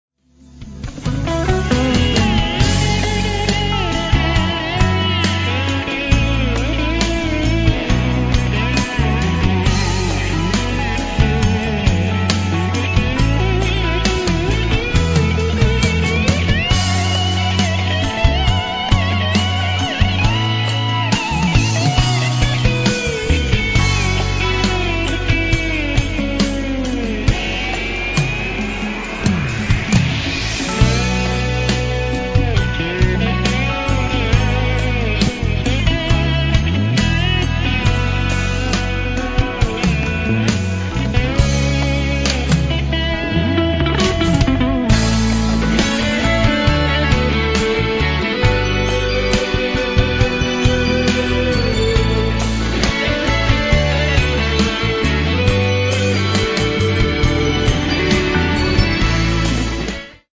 srednji tempo, slide gitara, pop refren